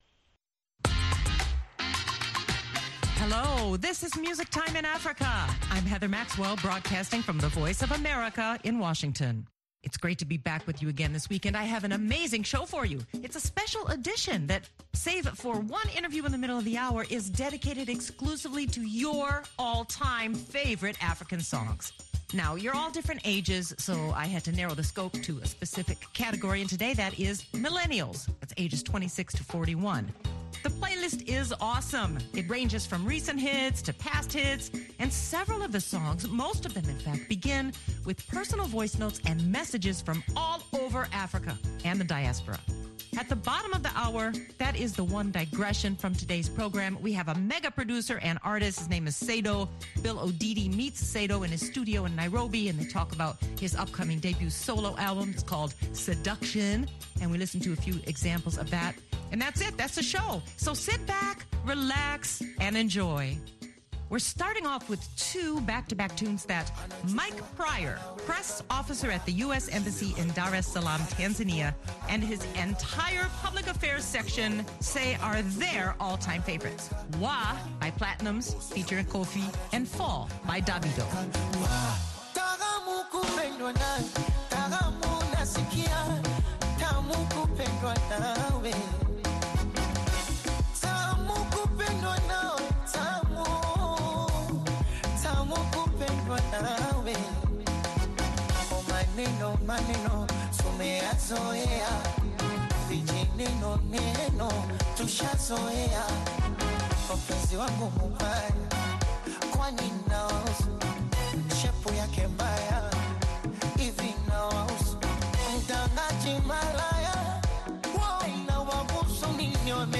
And then it’s back to more African millennial hits that will groove and sway you to the end of the hour.